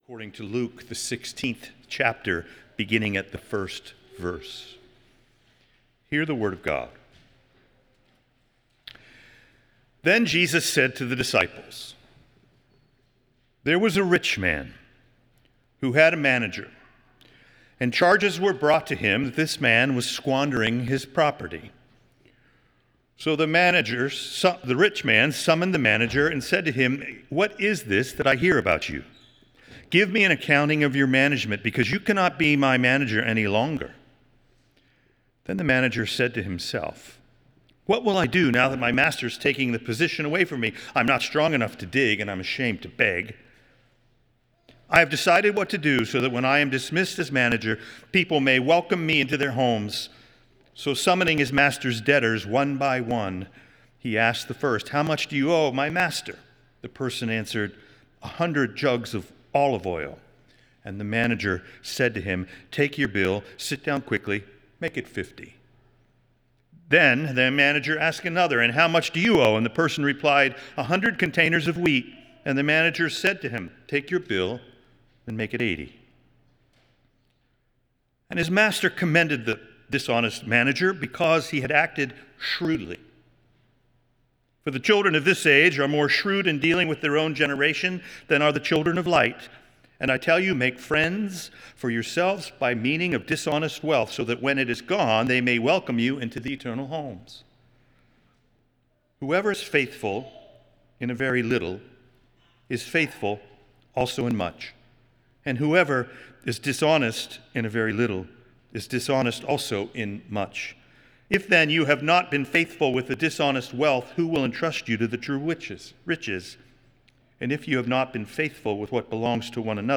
Nassau Presbyterian Church Sermon